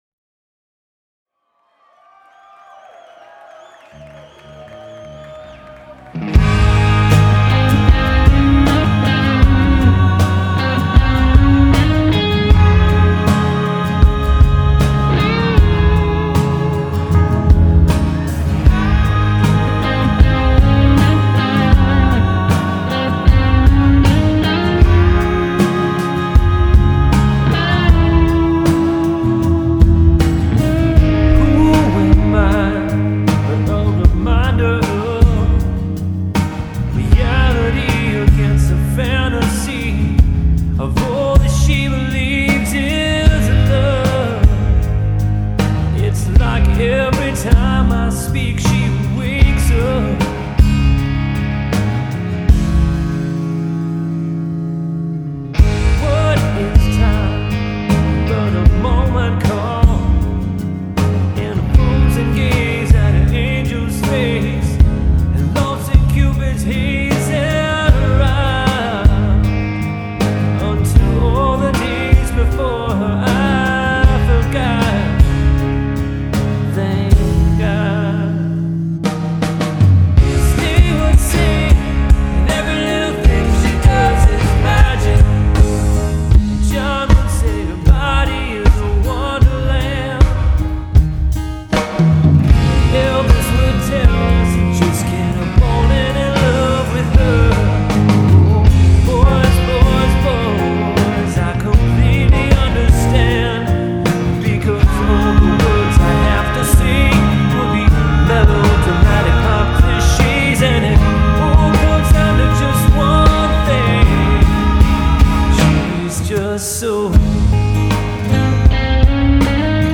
On the plus side, the song itself is neat, and I like the progressions. And the guitar tones are indeed scrumptious.